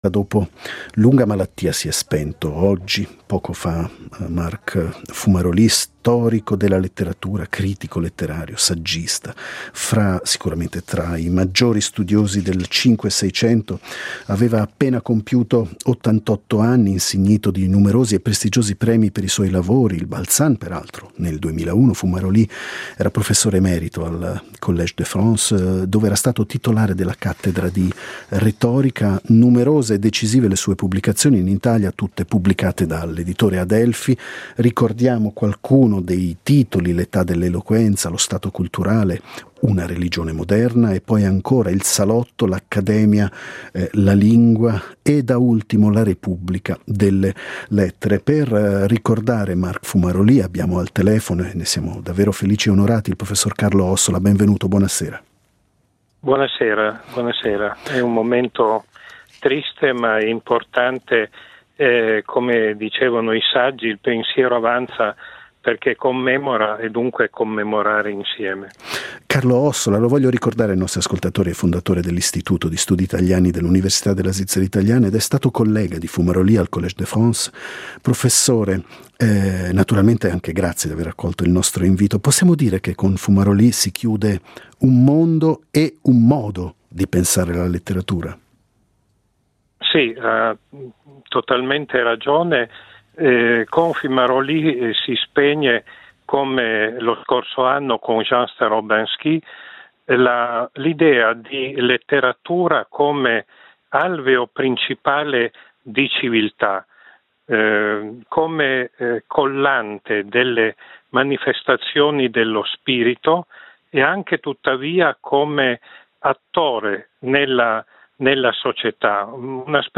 Emerito al Collège de France, aveva pubblicato in italiano presso Adelphi. Lo ricorda il collega al Collège Carlo Ossola.